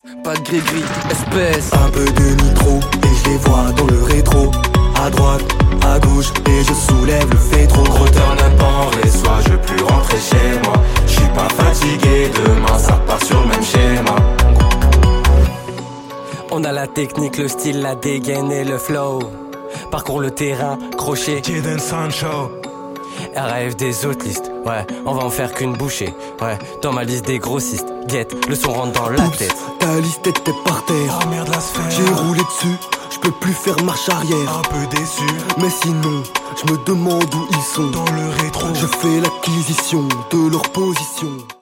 Après le studio ! ✨